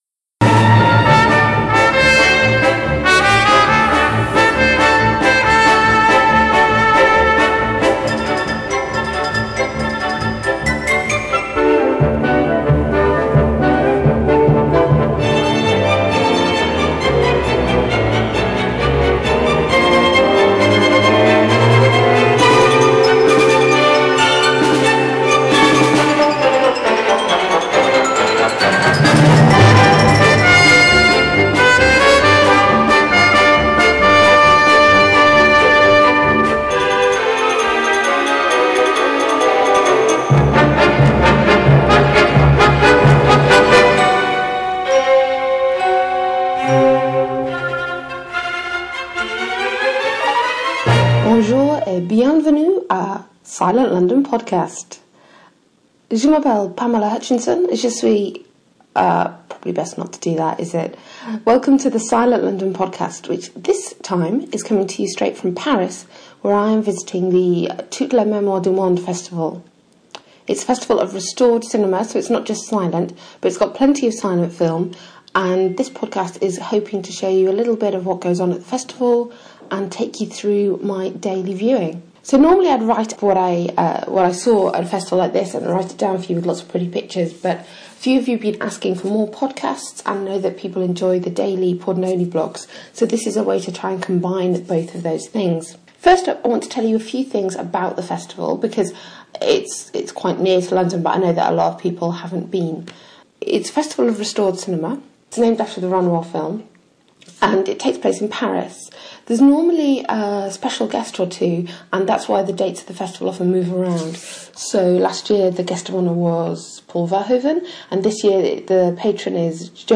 Welcome to the long-awaited return of the Silent London Podcast – coming to you straight from Paris.